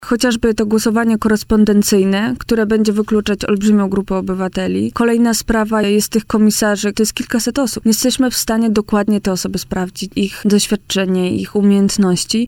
– Planowane jest również usunięcie możliwości korespondencyjnego głosowania – powiedziała w poranku „Siódma9” na antenie Radia Warszawa poseł klubu Kukiz’15 Elżbieta Zielińska.